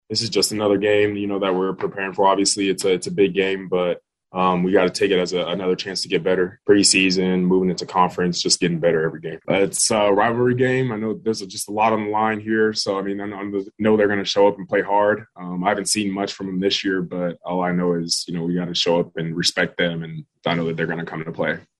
Ochai Agbaji says this is a chance to get better.